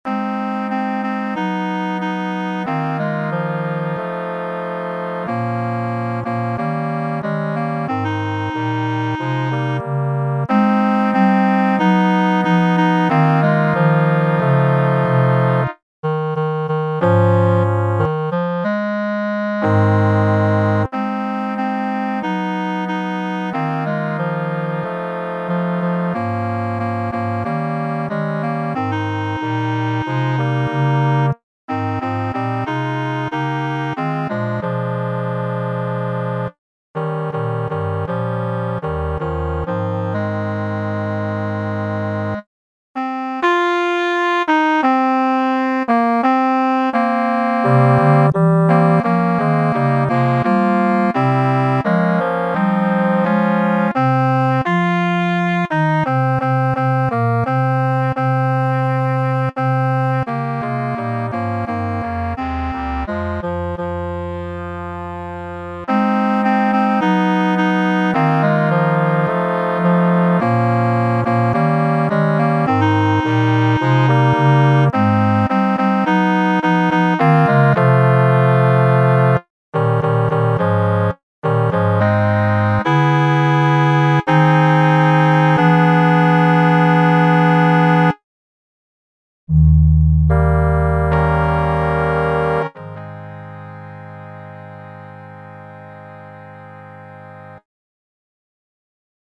TTBB (4 voix égales d'hommes) ; Partition choeur seul.
Tonalité : la bémol majeur